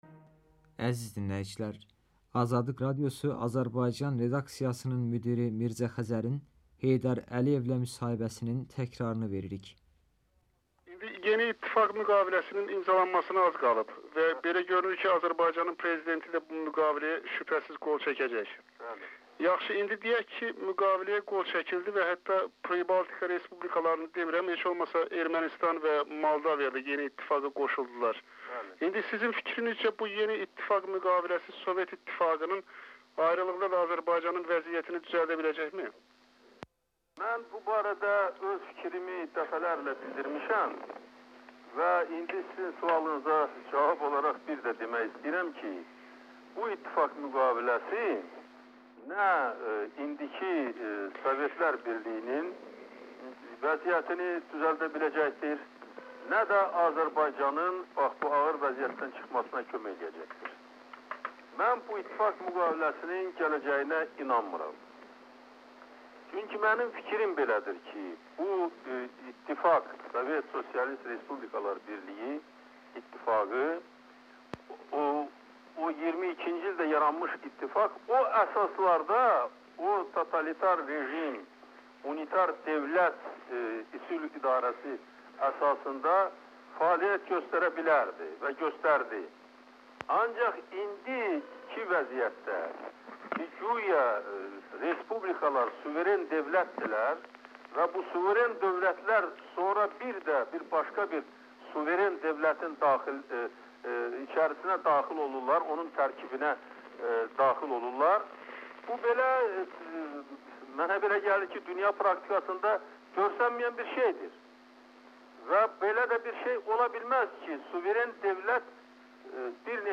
Heydər Əliyevlə müsahibə 13 avqust 1991-ci il